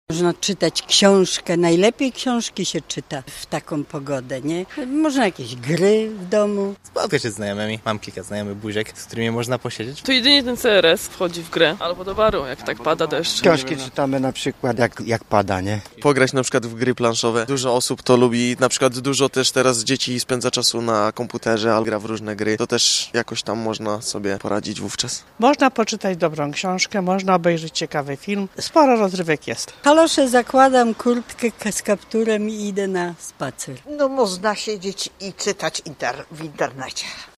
sonda-wakcje.mp3